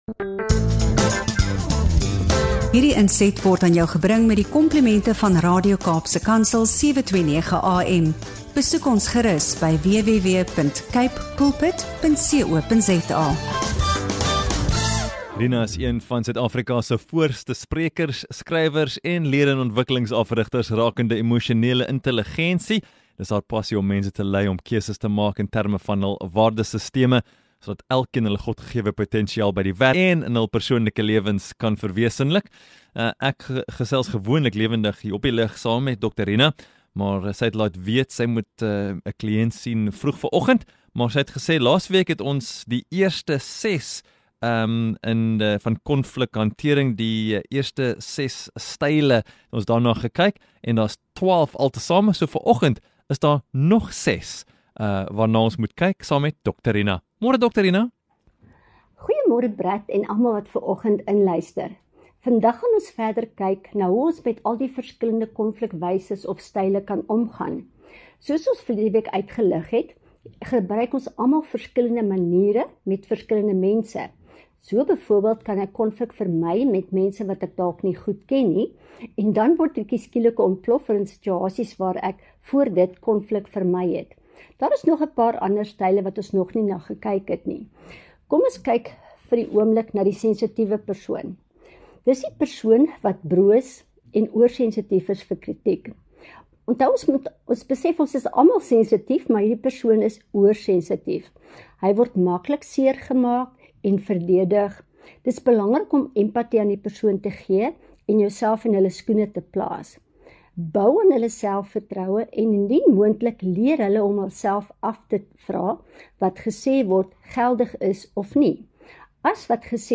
GET UP & GO BREAKFAST - INTERVIEW SPECIALS